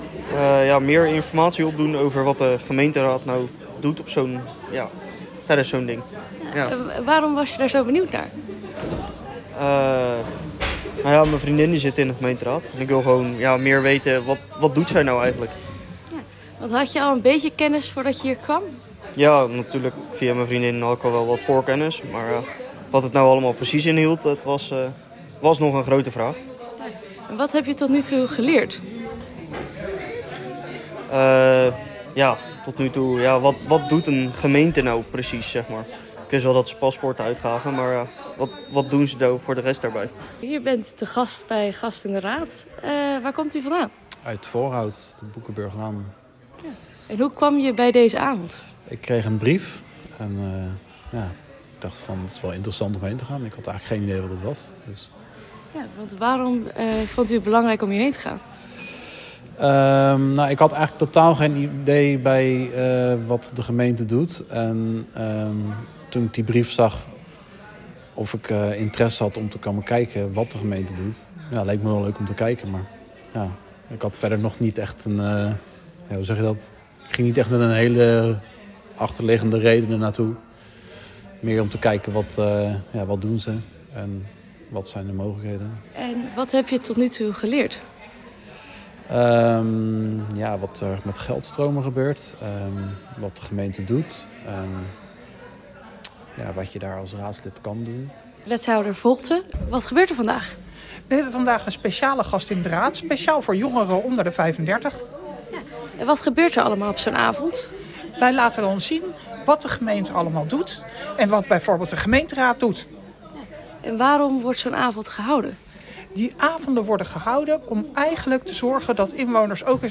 Audioreportage met twee aanwezigen en wethouder Marlies Volten: